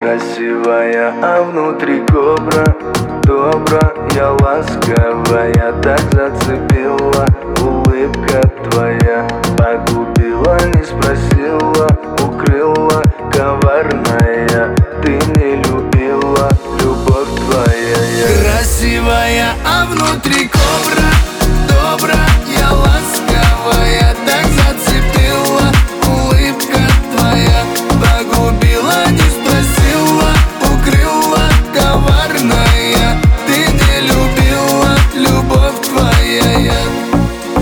• Качество: 320, Stereo
поп
гитара
мужской голос